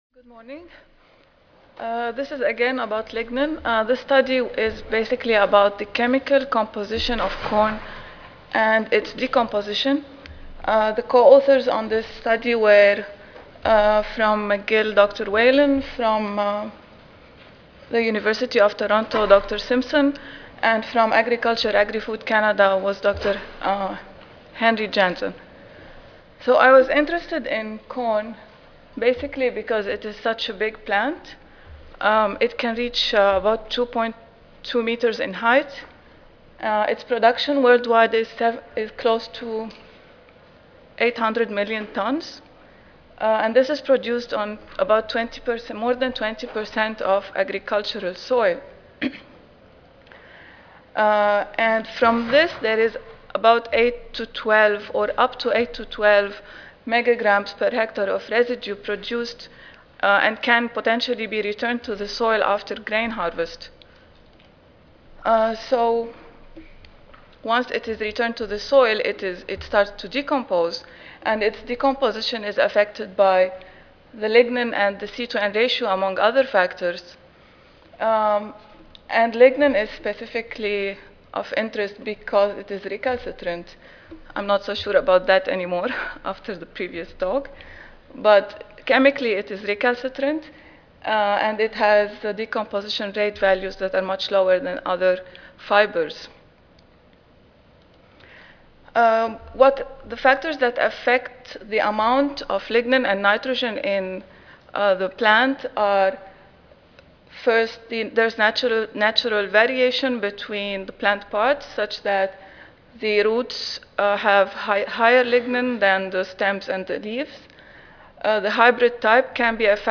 Long Beach Convention Center, Room 104A, First Floor
Audio File Recorded presentation